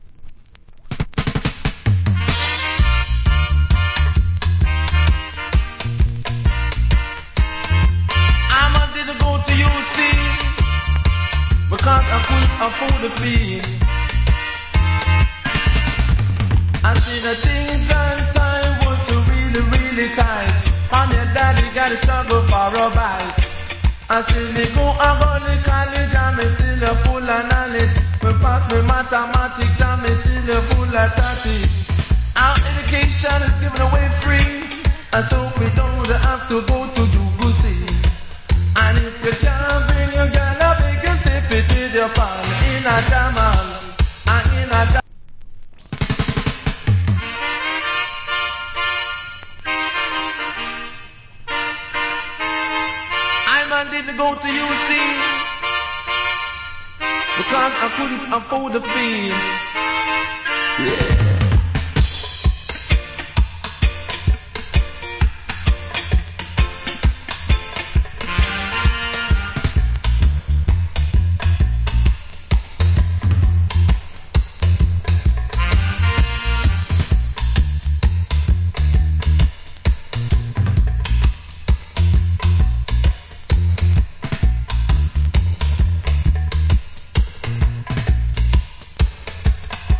Good DJ.